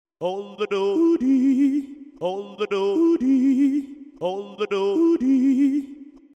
Klingelton
Erstellt mit Garageband und auf Iphone geladen